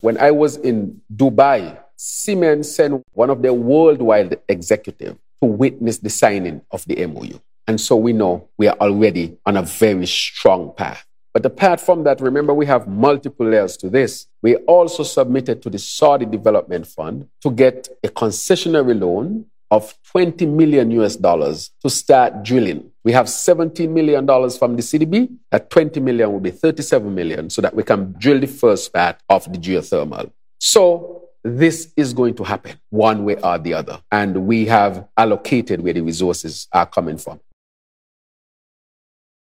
This he mentioned, during his roundtable discussion with the media, on April 24th: